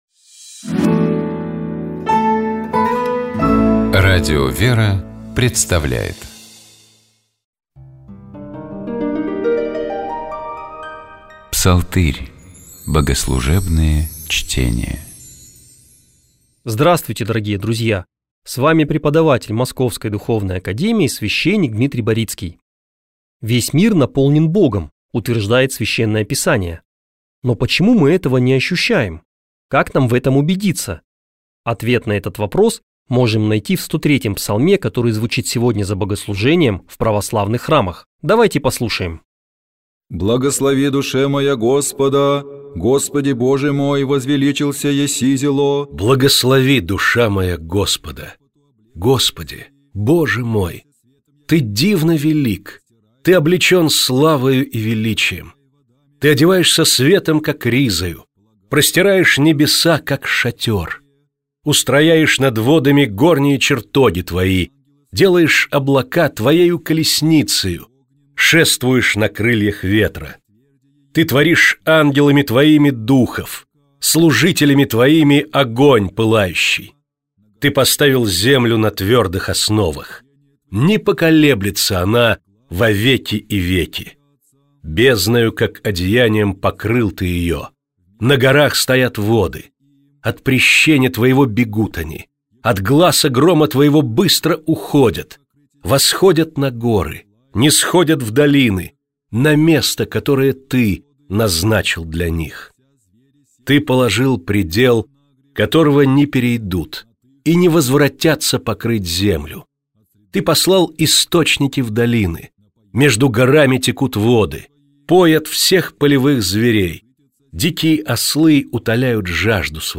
Псалом 103. Богослужебные чтения